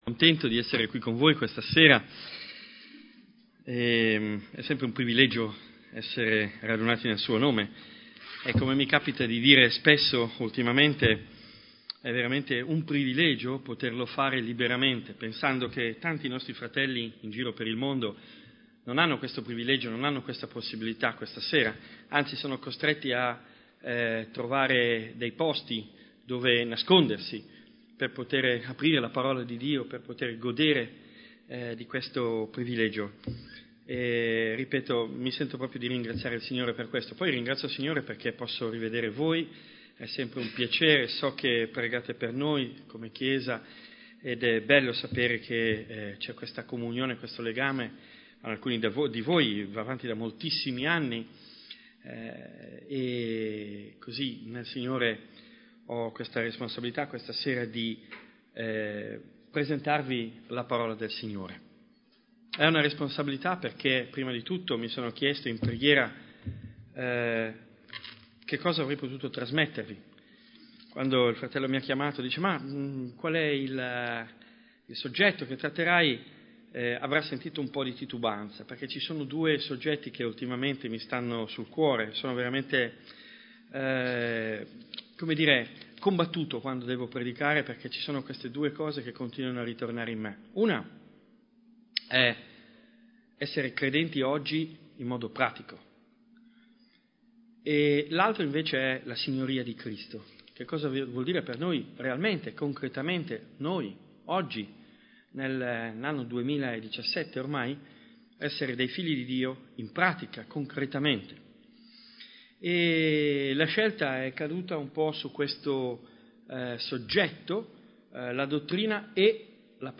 Tipo Di Incontro: Serie studi